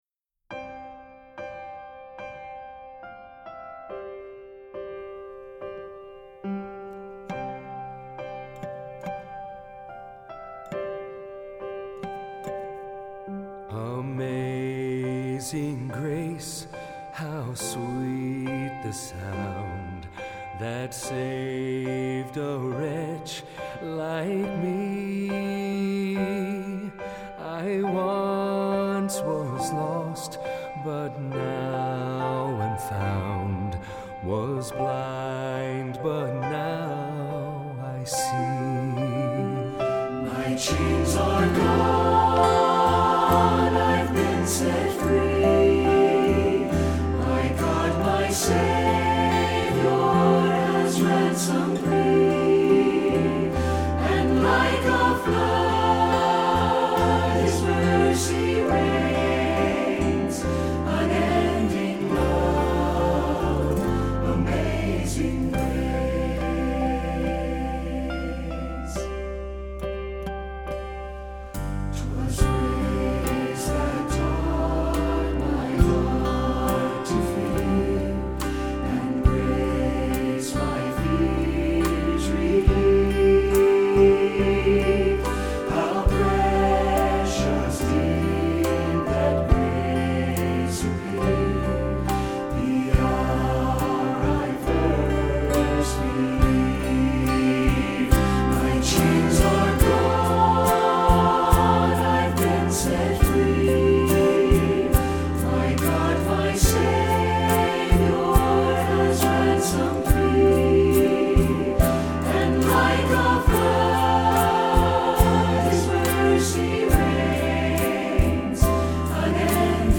Choral Collections
Voicing
SATB